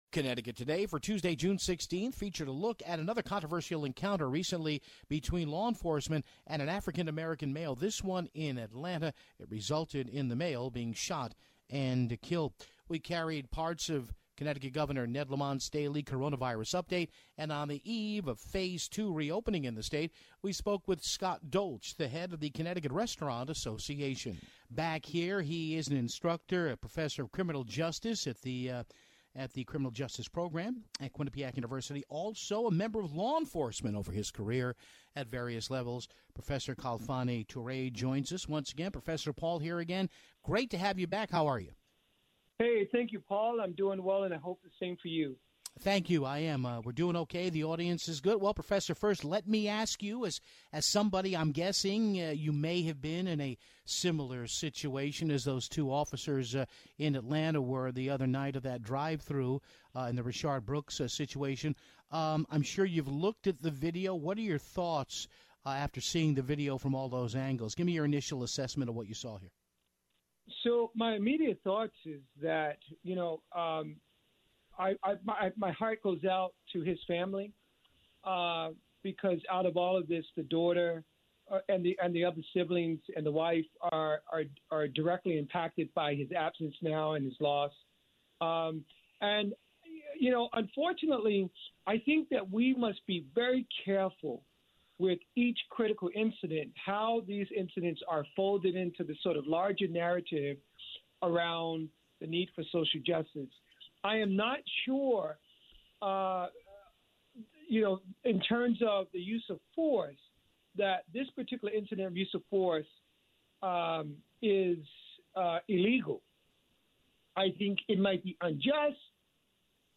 We carried Governor Ned Lamont's daily address to the media.